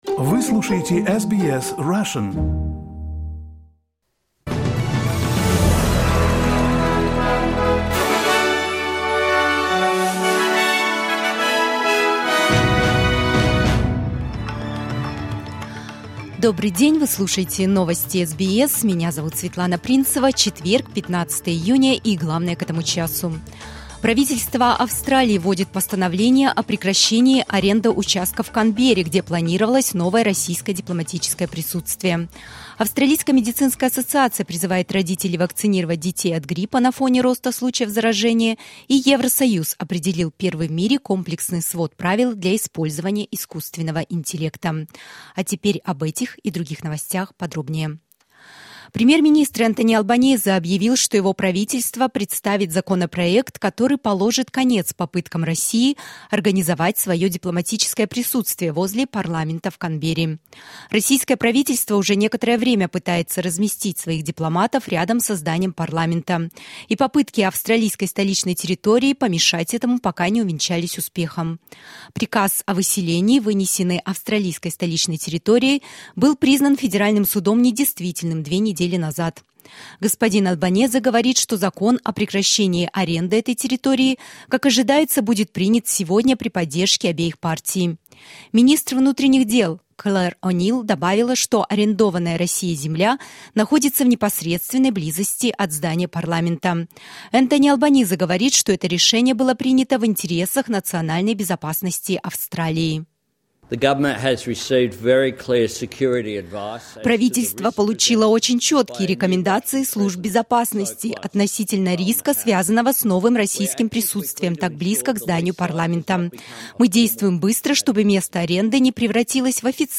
SBS news in Russian —15.06.2023